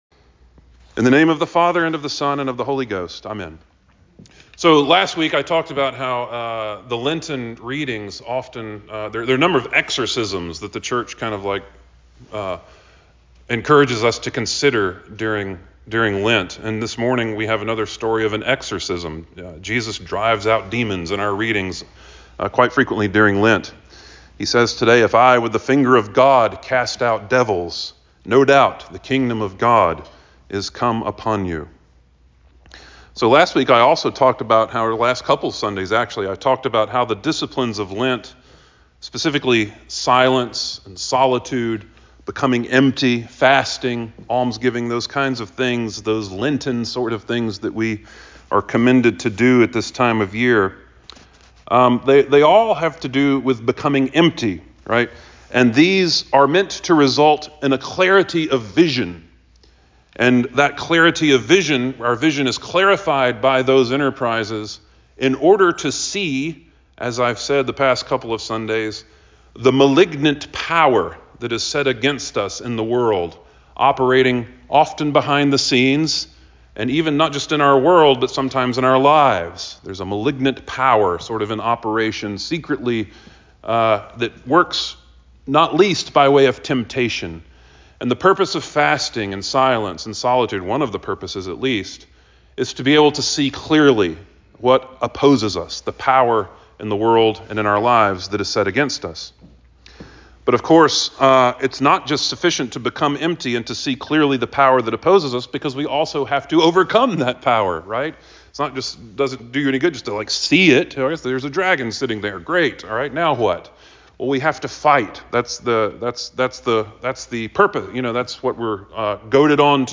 by All Saints Sermons